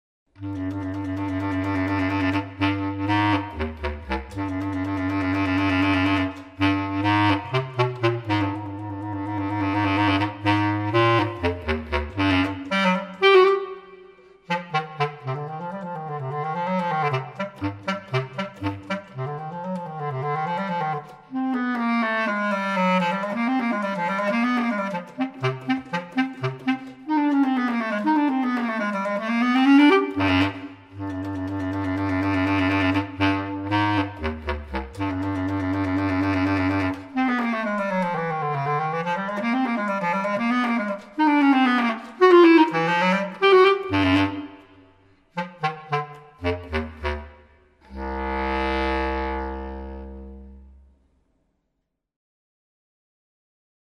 bass clarinet